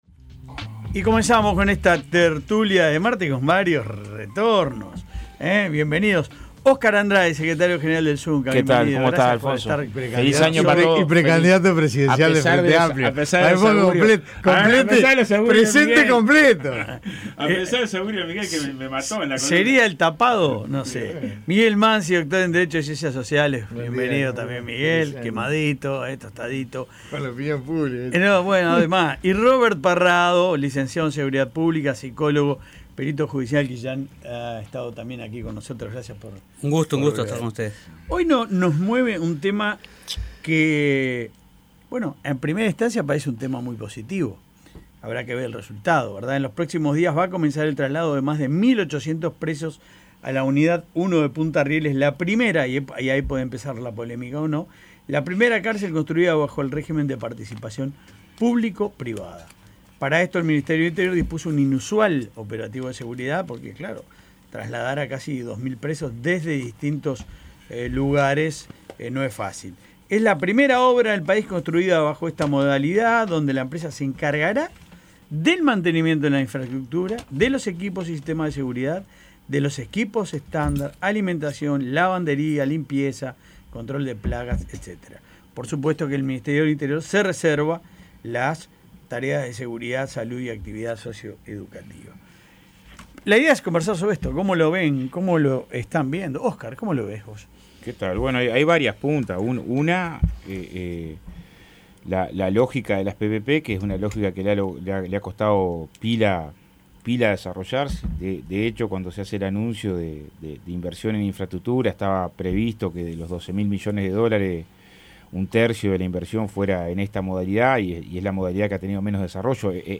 Escuche La Tertulia aquí: